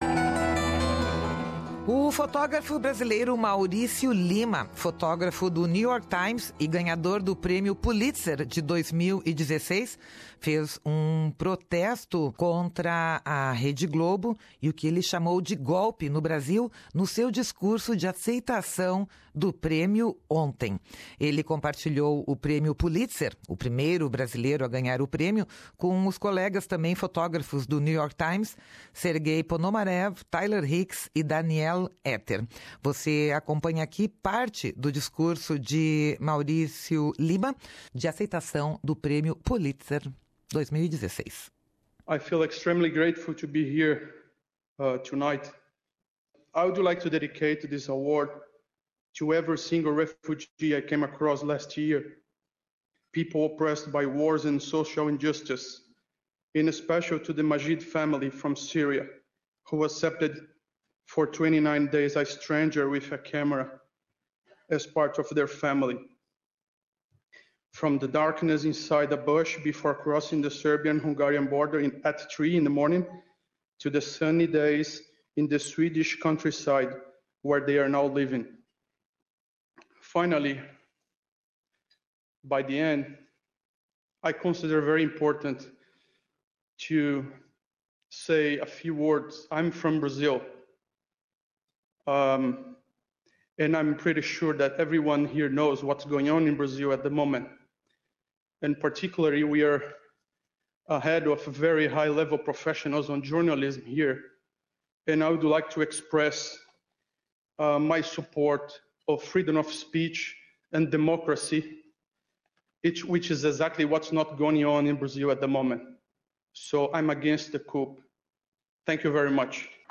Ouça aqui um excerto do discurso, em inglês.